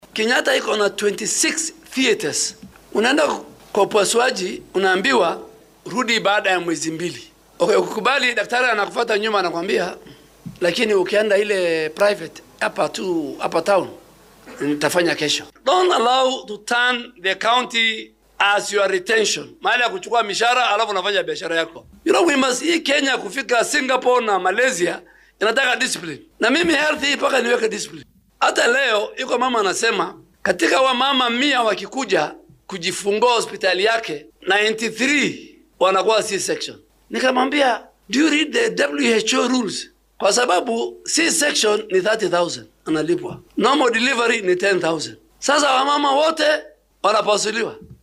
Wasiirka ayaa ka hadlayay ismaamulka Tharaka Nithi intii lagu guda jiray howlgalinta qayb cusub oo daryeelka degdegga ah oo aad u casrisan oo lagu soo kordhiyay Isbitaalka Chuka Heerka 5-aad.